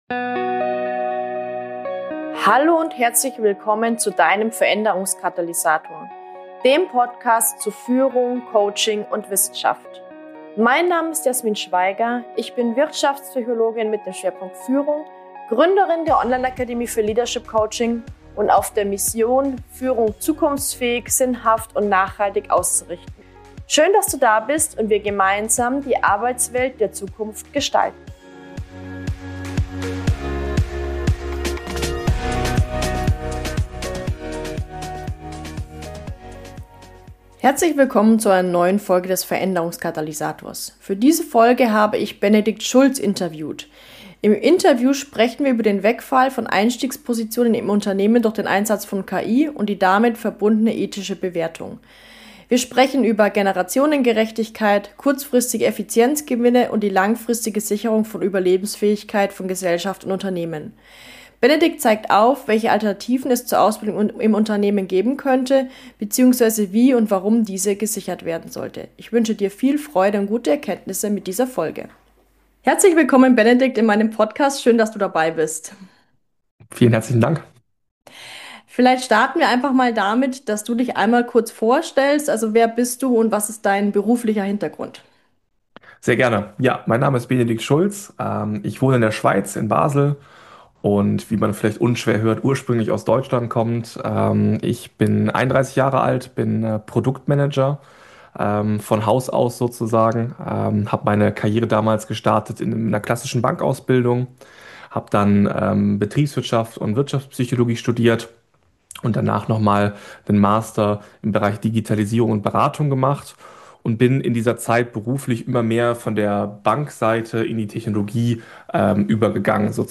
Im Interview sprechen wir über den Wegfall von Einstiegspositionen in Unternehmen durch den Einsatz von KI und die damit verbundene ethische Bewertung.